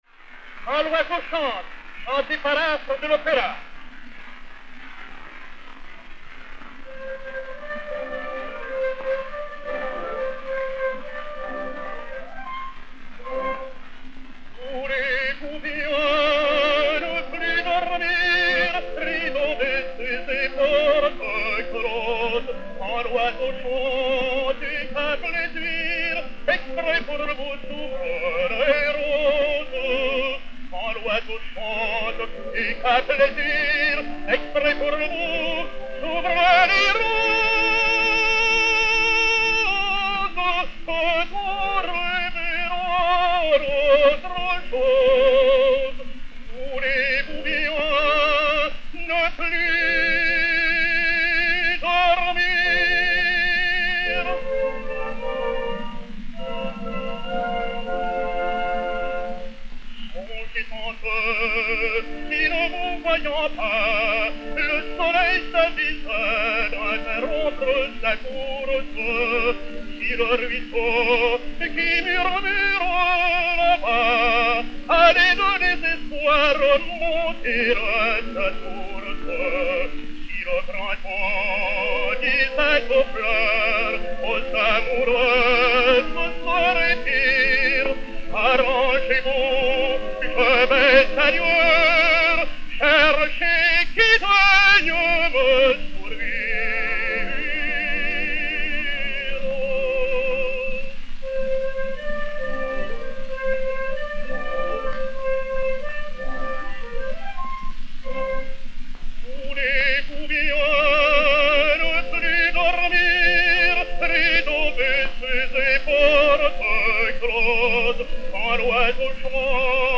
Orchestre
Pathé saphir 90 tours 3491, enr. à Paris en 1910